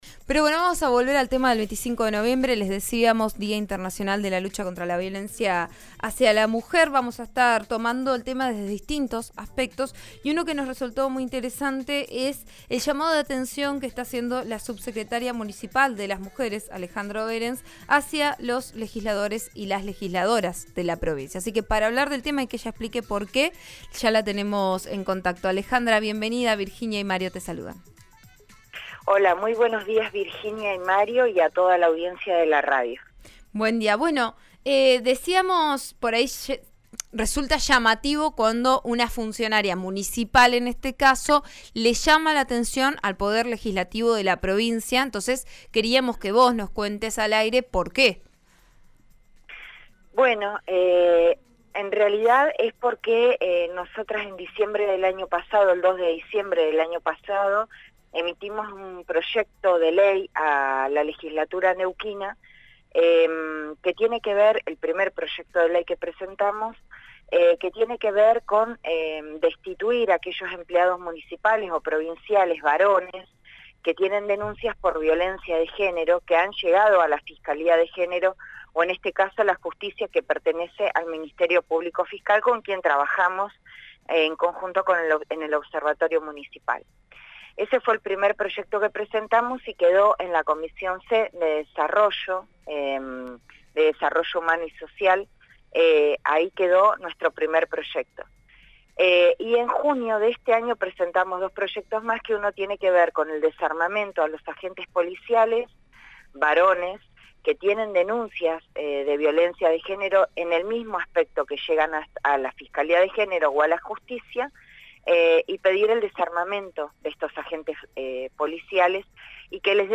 Algunos logramos que ingresen, pero otros estamos esperando que ingresen al fuero penal», solicitó Alejandra Oehrens, en declaraciones al programa Vos a Diario, de RN Radio (89.3).